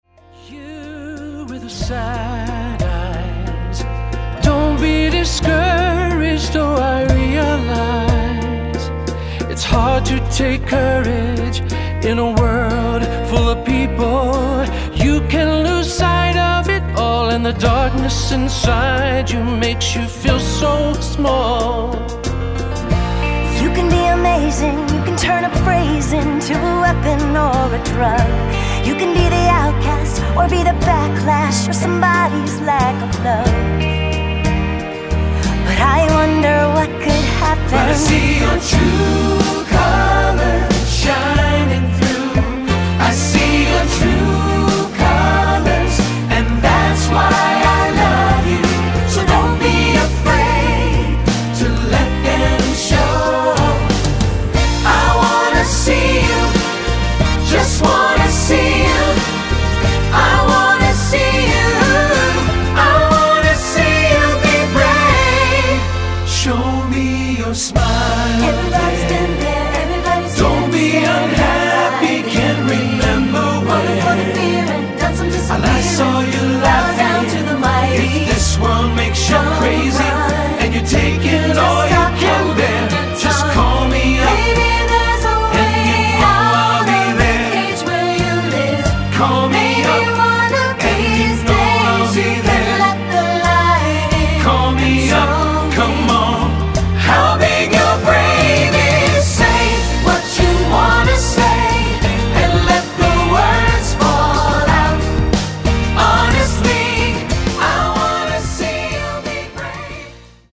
Choral Early 2000's Pop Graduation/Inspirational
SATB